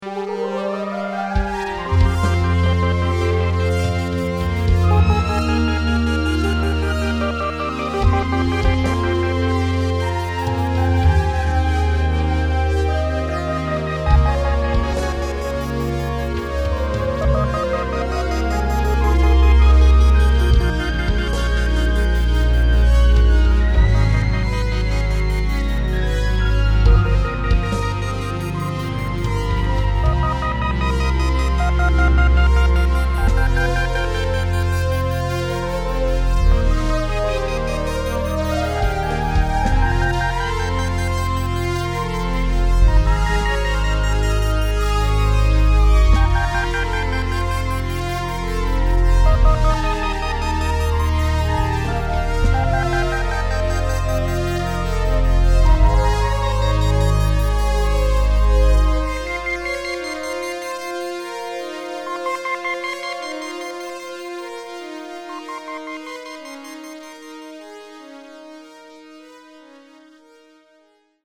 composed with step sequences